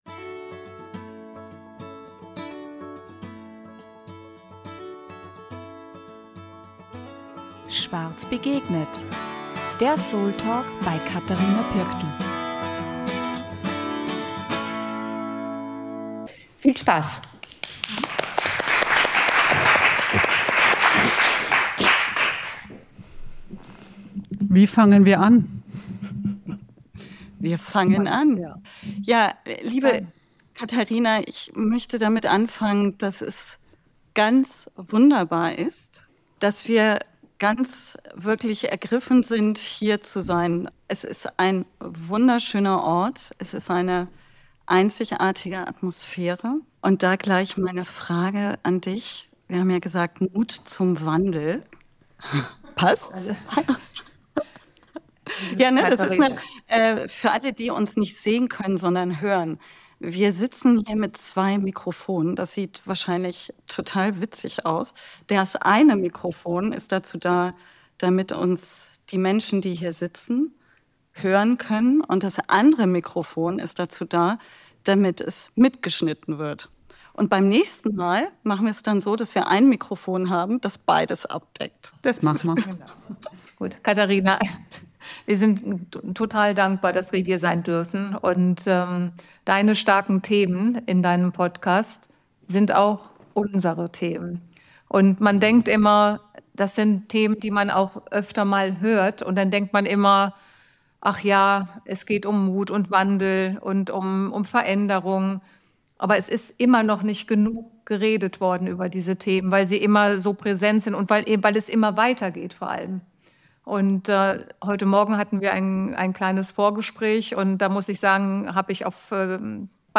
Hinweis: Da es unsere allererste Live-Aufnahme war, bitten wir um Nachsicht, falls der Ton an manchen Stellen nicht ganz klar ist.